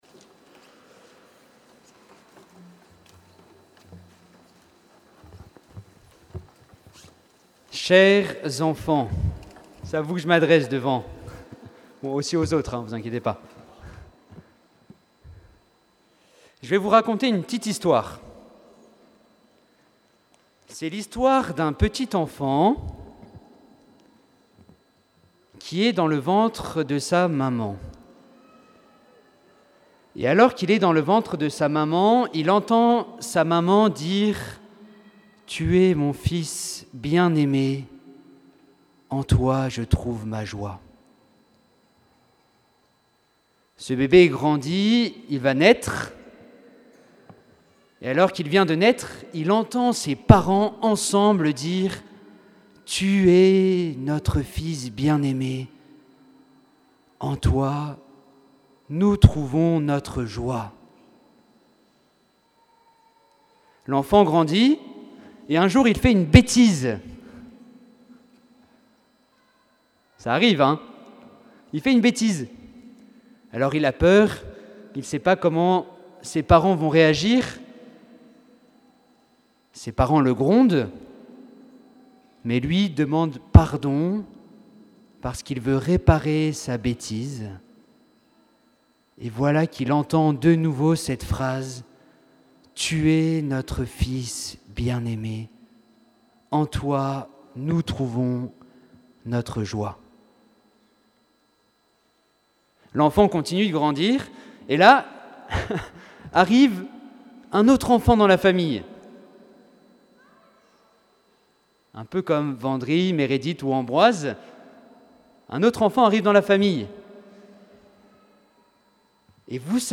ICI, homélie du 11 janvier 2026